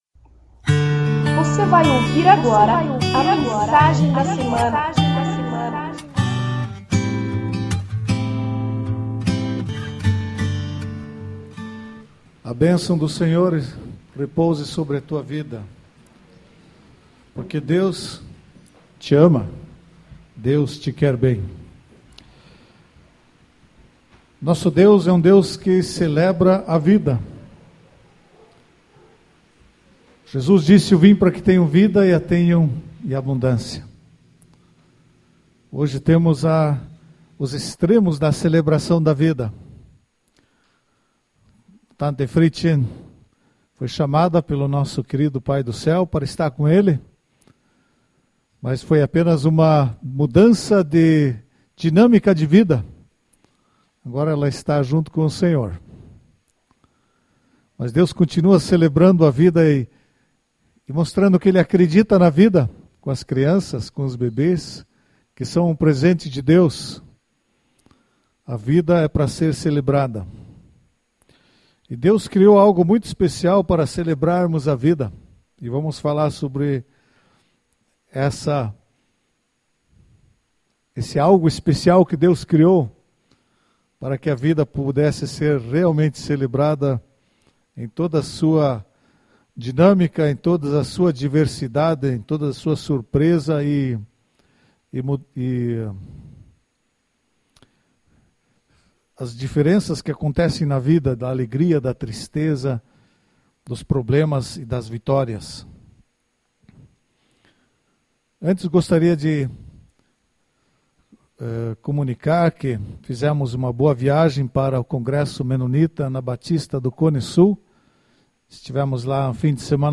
Mensagem em áudio deste domingo, dia 02/02.